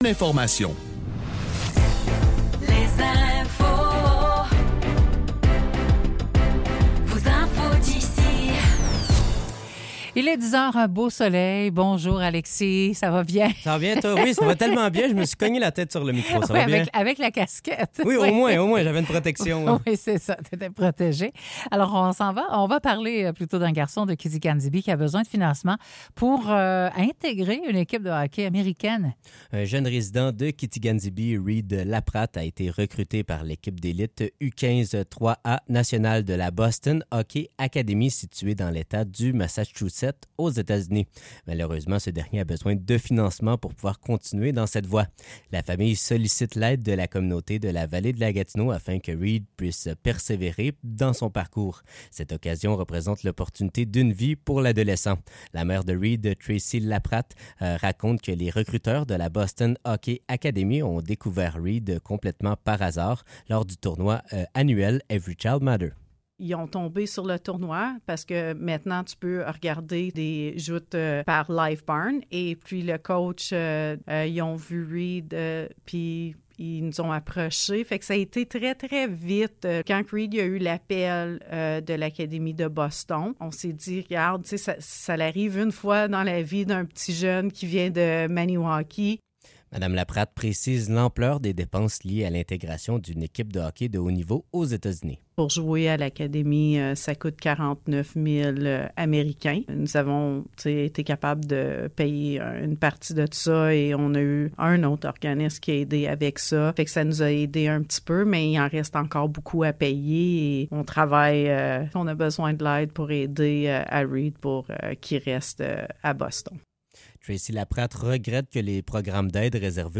Nouvelles locales - 15 novembre 2024 - 10 h